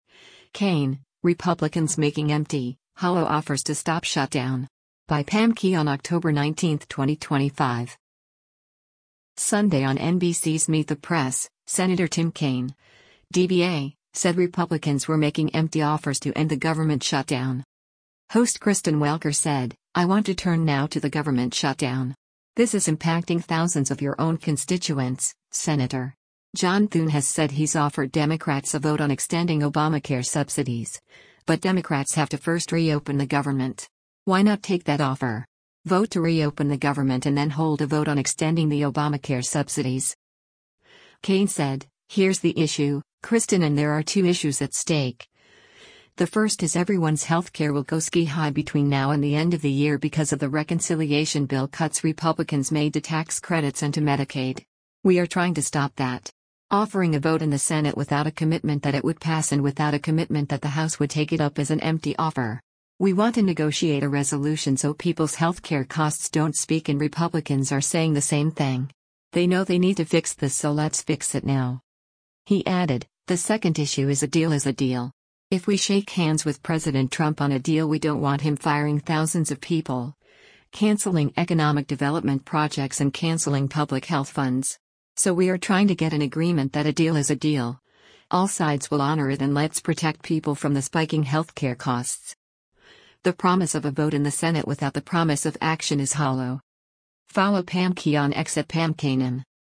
Sunday on NBC’s “Meet the Press,” Sen. Tim Kaine (D-VA) said Republicans were making empty offers to end the government shutdown.